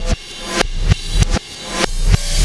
Black Hole Beat 12.wav